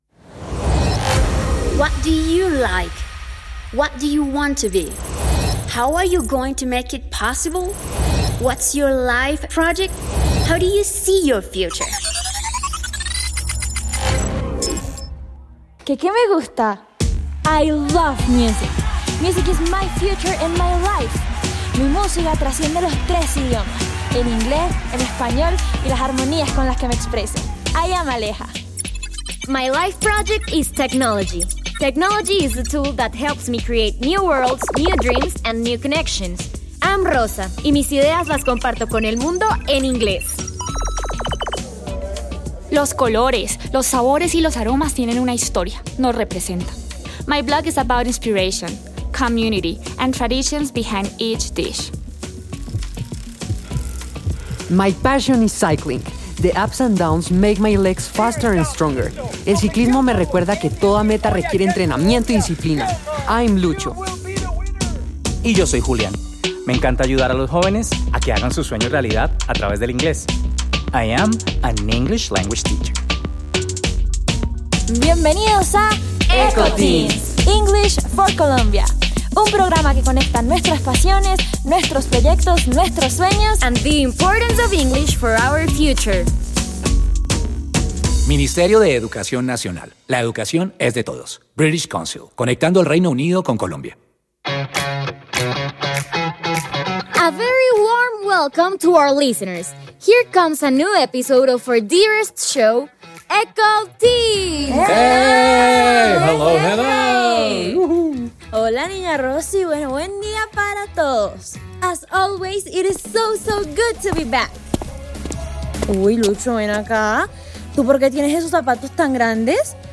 Ir a mis descargas Eco Teens What's the matter programa radial